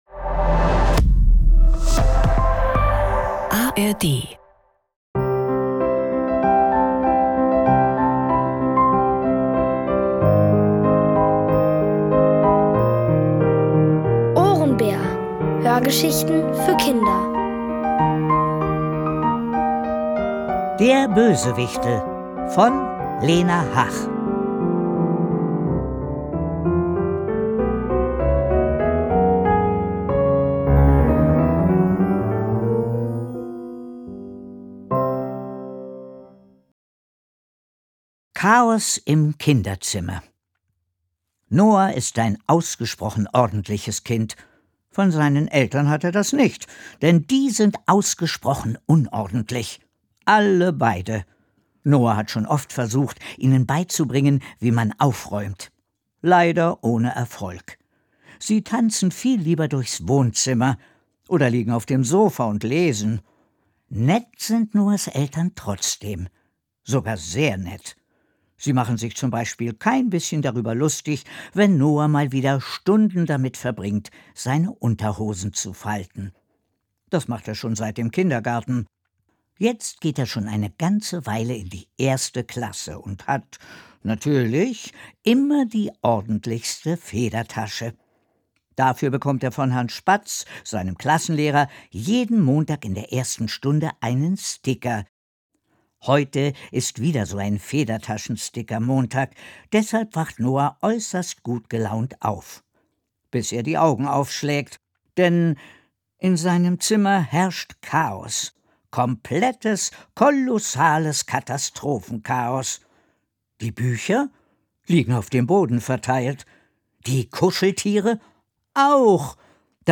Der Bösewichtel | Die komplette Hörgeschichte! ~ Ohrenbär Podcast
Es liest: Santiago Ziesmer.